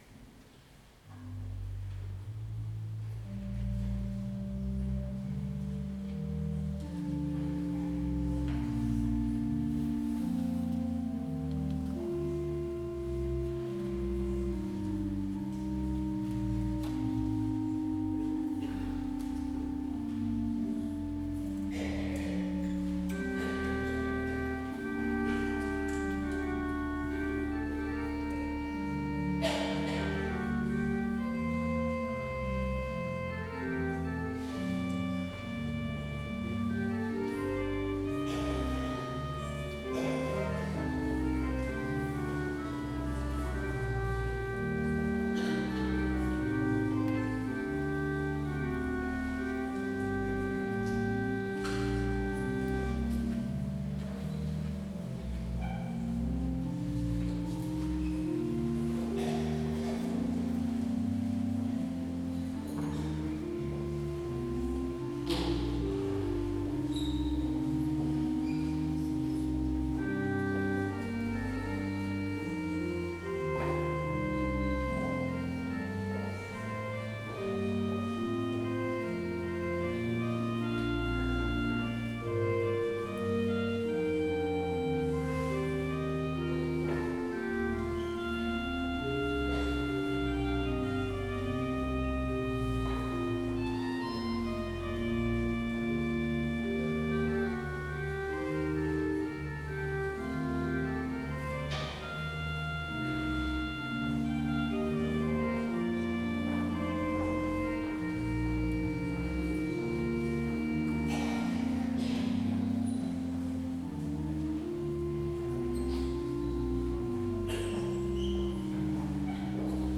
Heropening Sint-Pieterskerk Rotselaar
Num komm der heiden heiland (enkel orgel)